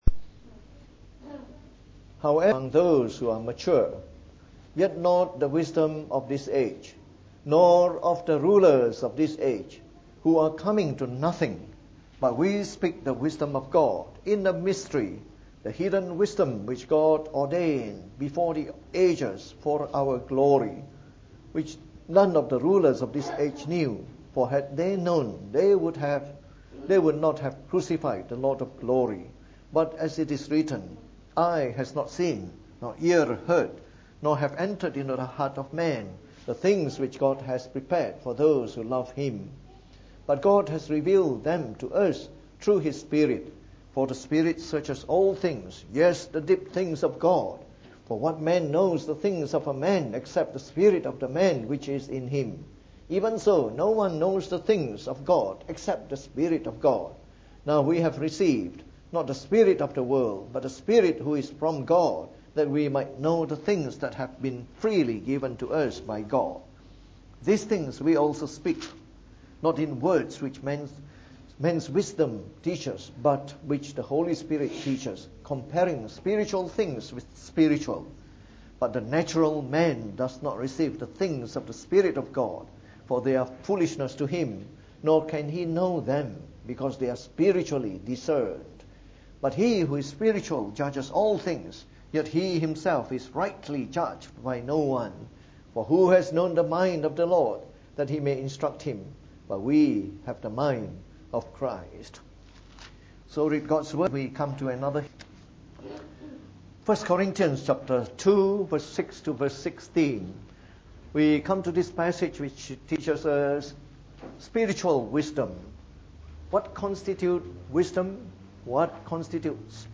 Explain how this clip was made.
From our series on 1 Corinthians delivered in the Evening Service.